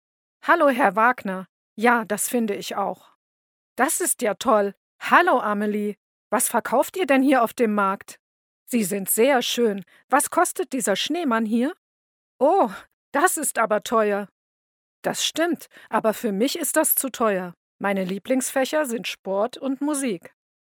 German female voice  talent locutor